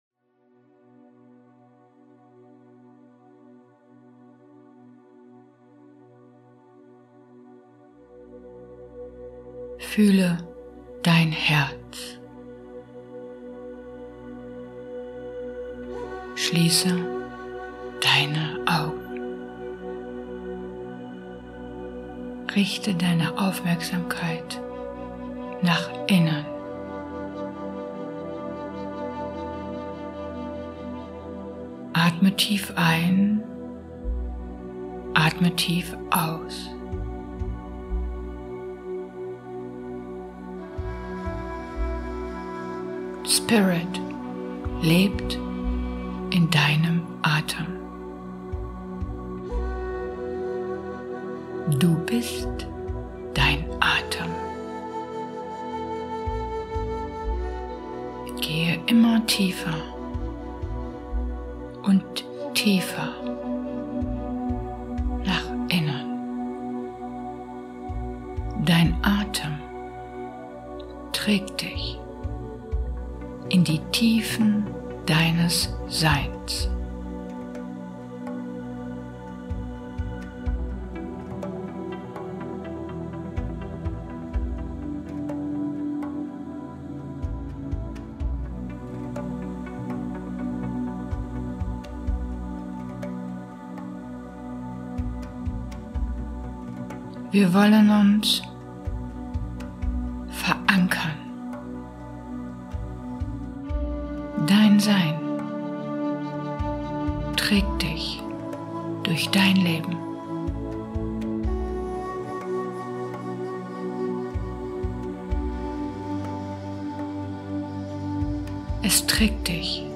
Vielen Dank für Dein Interesse an dieser Meditation